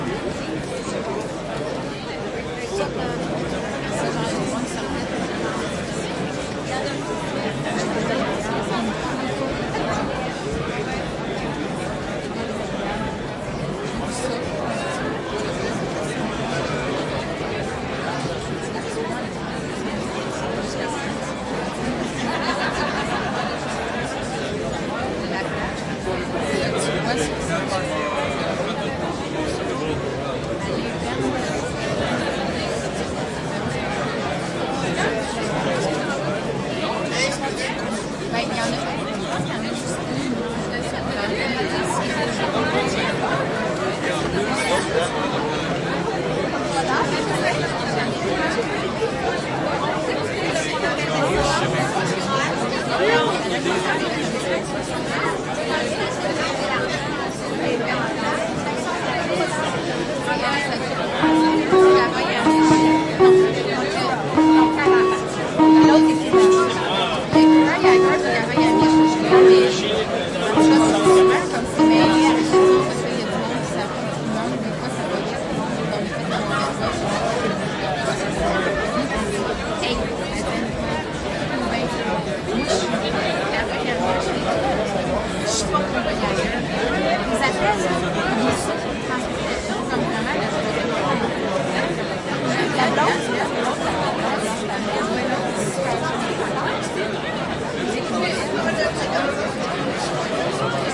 蒙特利尔 " 人群中的大剧院大厅 魁北克的声音5 蒙特利尔，加拿大
描述：人群int大剧院大厅魁北克voices5蒙特利尔，Canada.flac
标签： 蒙特利尔 大堂 魁北克 声音 INT 人群 戏剧 加拿大
声道立体声